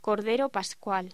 Locución: Cordero pascual
voz